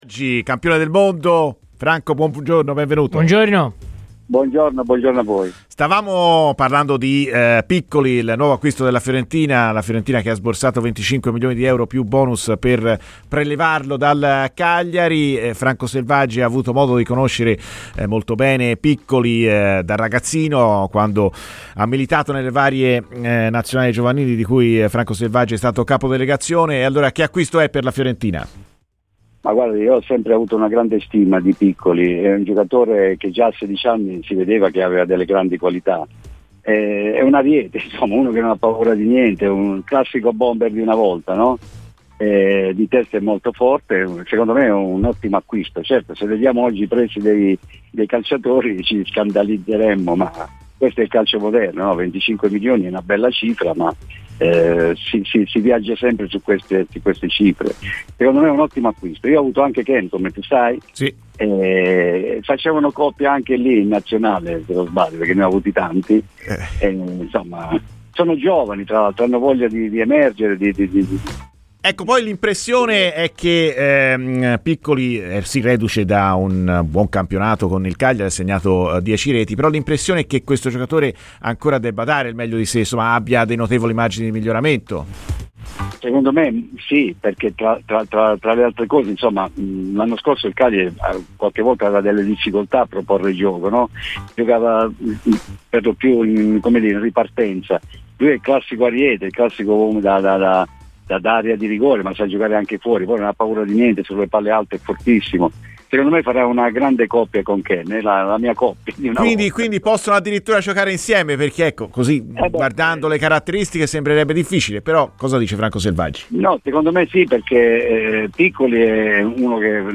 Durante la diretta di Viola Amore Mio è intervenuto ai microfoni di Radio FirenzeViola l'ex Campione del Mondo '82 Franco Selvaggi che ha conosciuto molto da vicino il nuovo acquisto viola Roberto Piccoli, grazie alla sua esperienza all'interno della FIGC e dunque avendolo seguito nelle categorie giovanili azzurre, e lo racconta così: "Piccoli è un ariete, un classico bomber d'area di rigore, farà una grandissima coppia con Moise Kean."